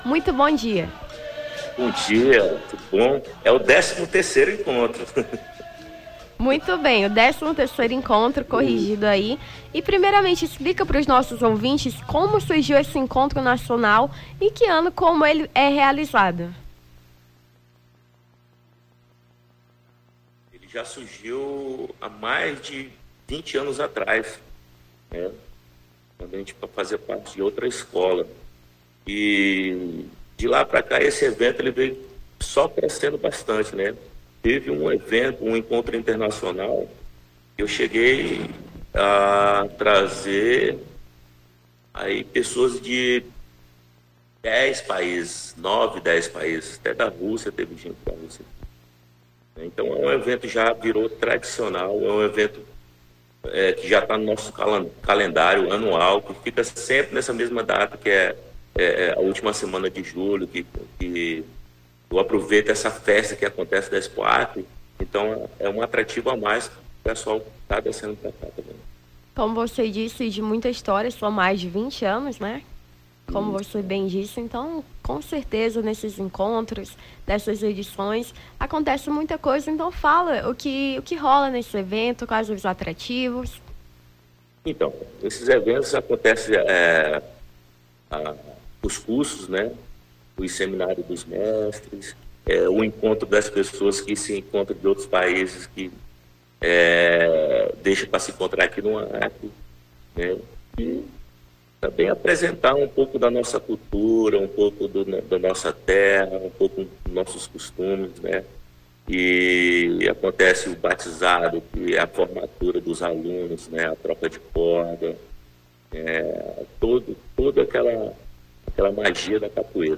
Nome do Artista - CENSURA - ENTREVISTA (XII ENCONTRO CAPOEIRA) 26-07-23.mp3